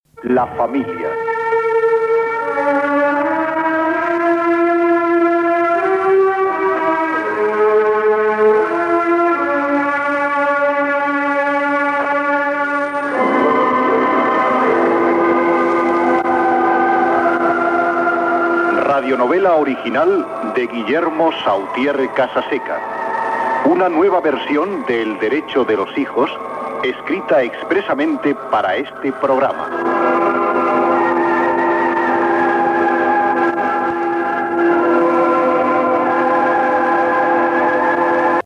Careta de la ràdio novel·la.
Ficció